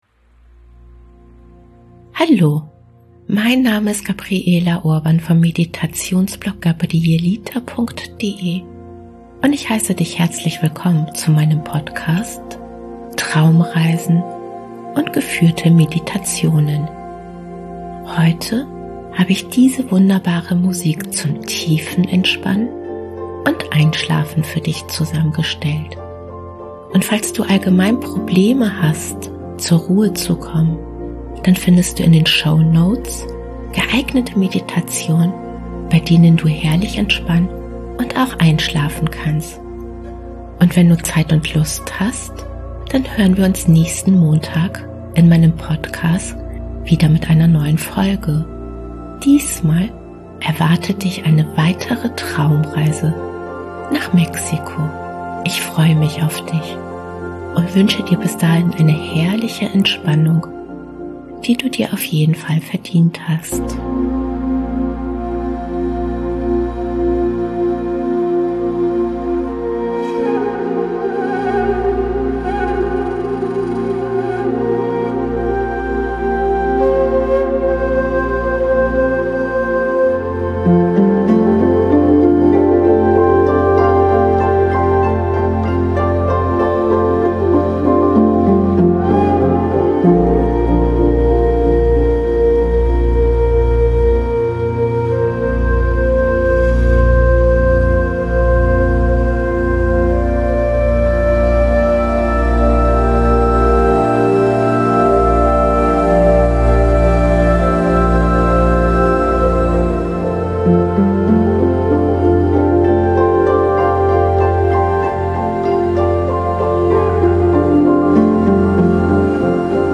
Tiefenentspannung Musik zum Einschlafen
Heute habe ich diese wunderbare Musik zum Tiefenentspannen und Einschlafen für dich zusammengestellt.